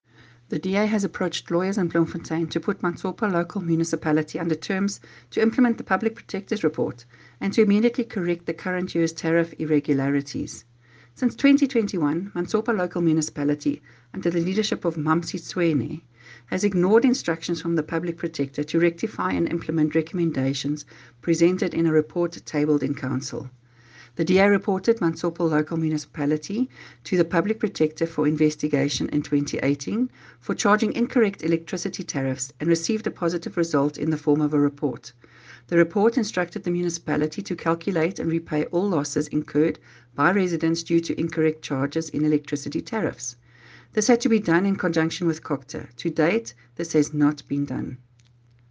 Afrikaans soundbites by Cllr Tania Halse
Eng-voicenote-2.mp3